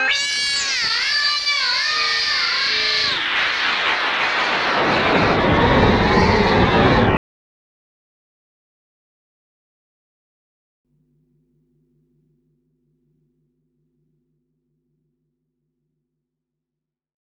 10 Rhodes FX 004.wav